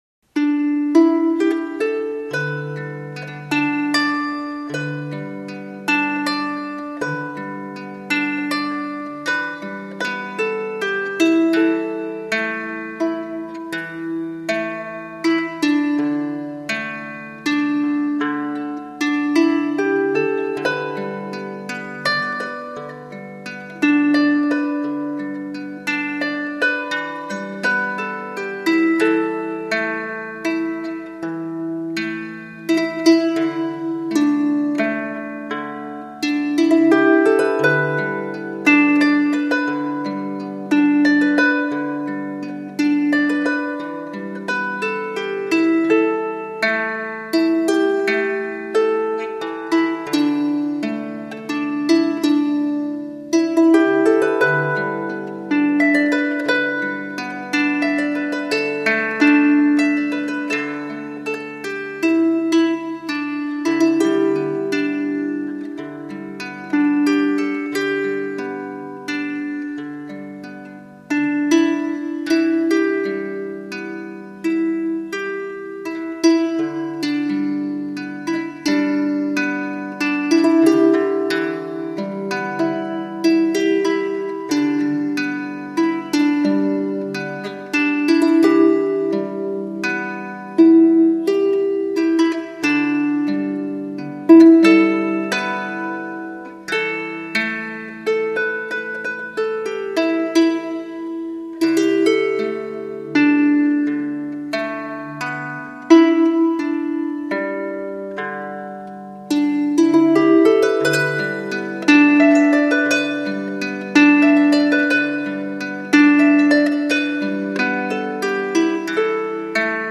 It’s still as powerful now, if not more so–so powerful in fact, that I remembered the whole poem, even though I thought I’d have to read rather than recite it…
Accompanying instrument: Lady – tenor Flea ukulele